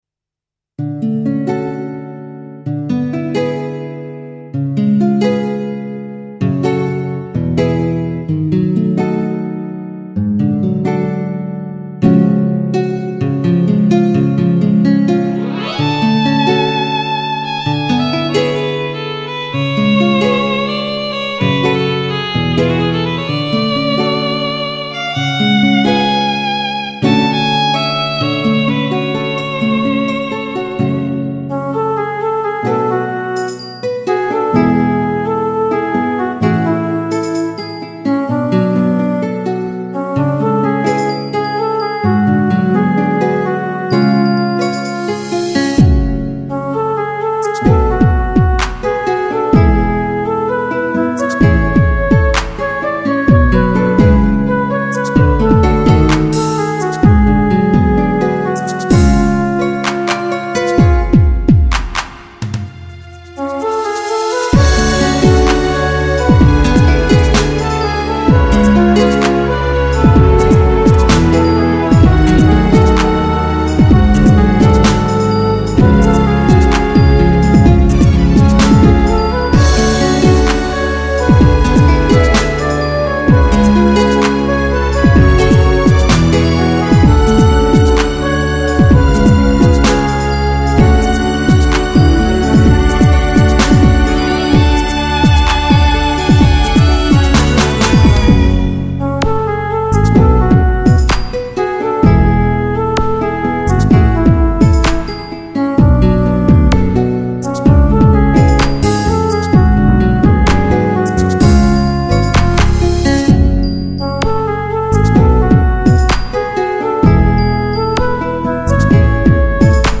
2004年　9月・・・バイオリンをフューチャーしたバラード
’70年代後半〜’８０年代前半の歌謡バラードって感じっすかね〜